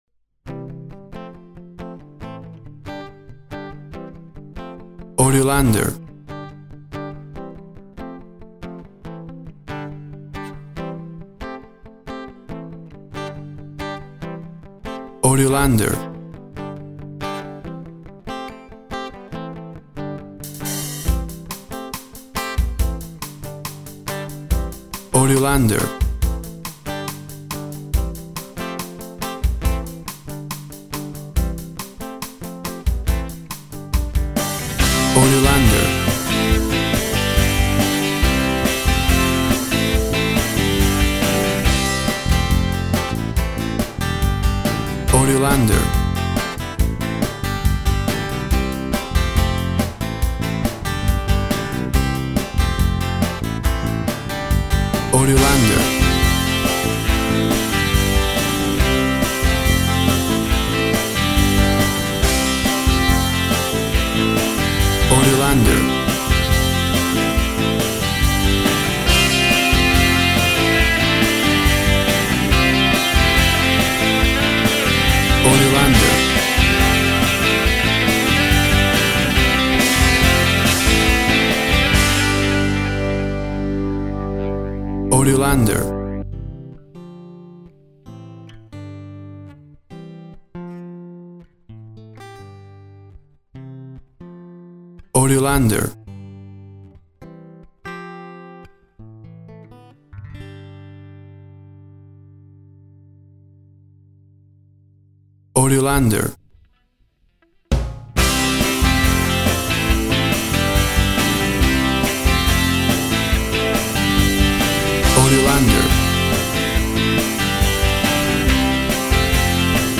WAV Sample Rate 16-Bit Stereo, 44.1 kHz
Tempo (BPM) 140